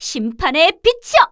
cleric_f_voc_skill_grandcross.wav